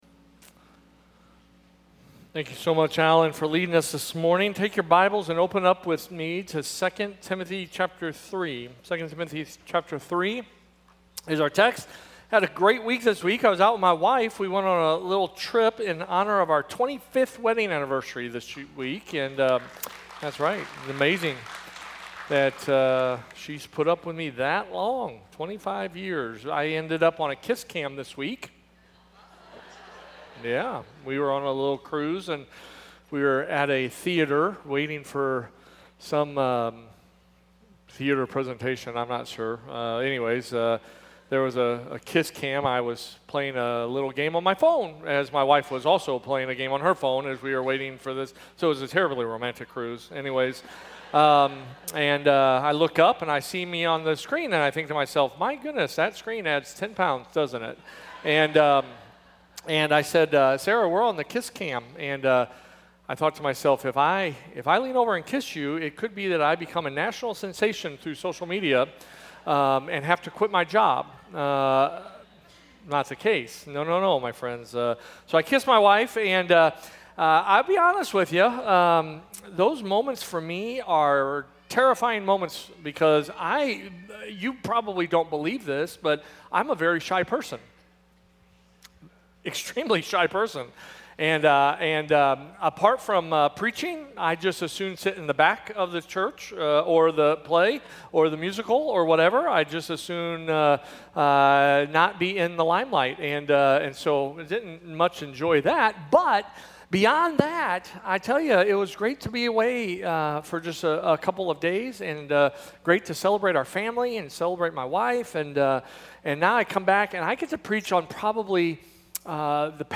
A message from the series "Unshakable."